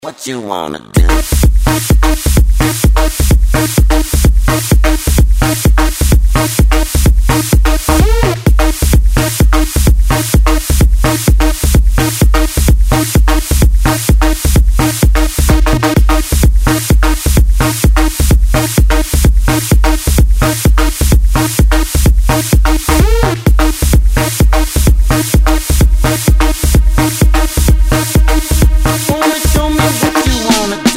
Клубные рингтоны Загрузил